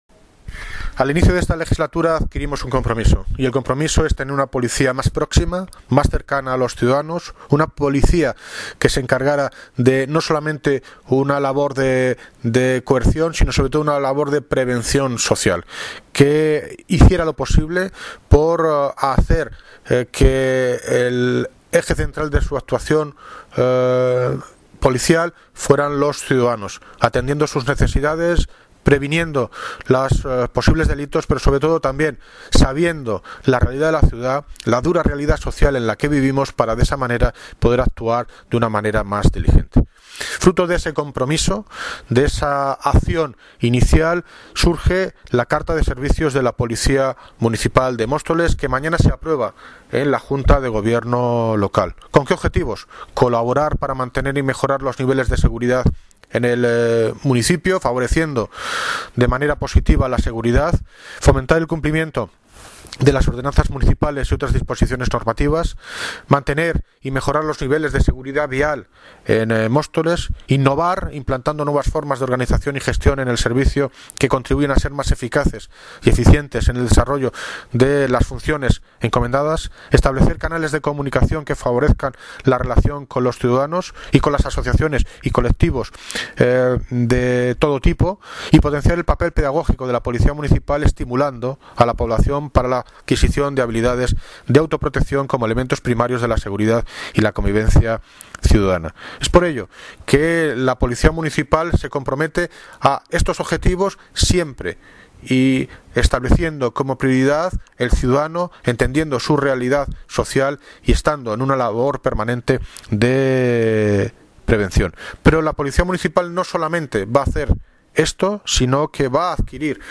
Audio - David Lucas (Alcalde de Móstoles) Sobre carte de servicios Policía Municipal